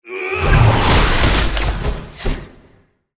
GameMpassetsMinigamesCjsnowEn_USDeploySoundGameplaySfx_mg_2013_cjsnow_attacksenseiwater.mp3